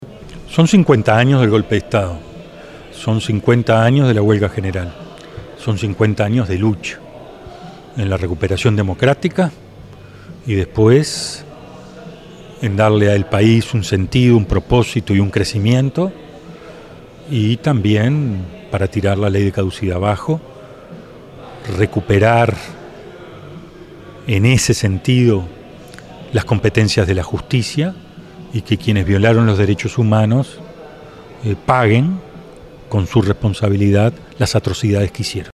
Conversatorio sobre memorias y resistencias sobre terrorismo de Estado en el Centro Cultural de Pando
rafael_michelini.mp3